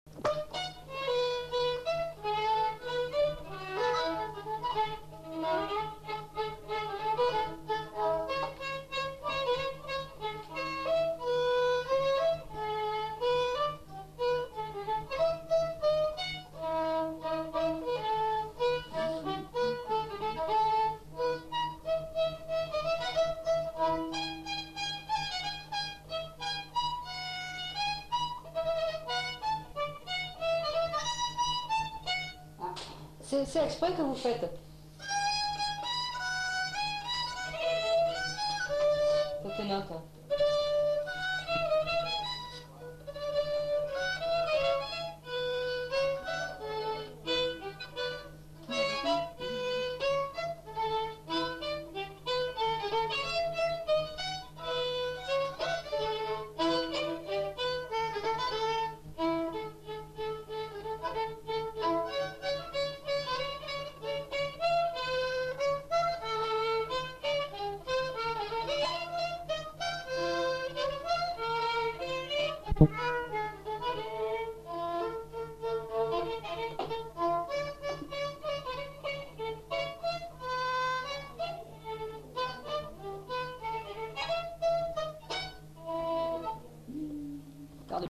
Lieu : Saint-Michel-de-Castelnau
Genre : morceau instrumental
Instrument de musique : violon
Danse : scottish
Notes consultables : 2 violons. Accélération de la bande.